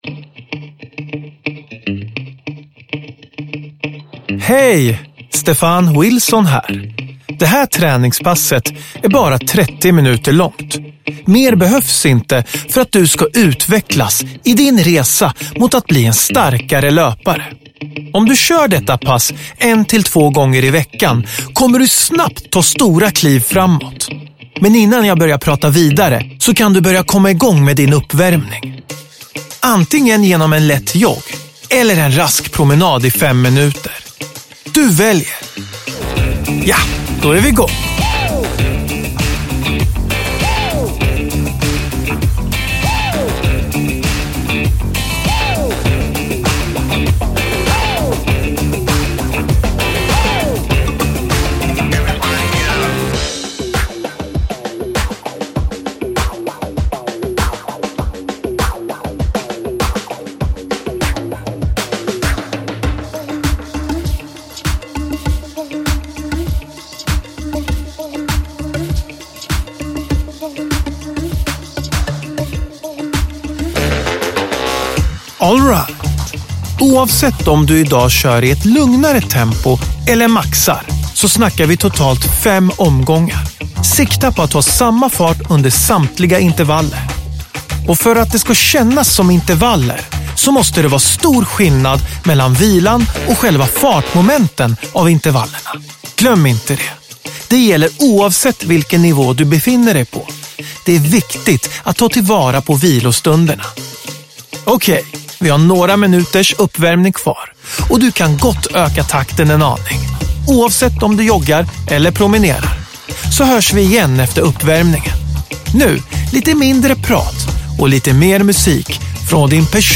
Ett 30 minuter långt pass med intervallträning där du springer lika länge som du vilar. Var beredd på en tuff men härlig löprunda på dina villkor, där du har hälsoinspiratören Stephan Wilson i ditt öra som en peppande träningskompis hela vägen in i mål.
Stephan Wilson är med dig under hela passet, han vägleder och hejar på till peppande musik.
Uppläsare: Stephan Wilson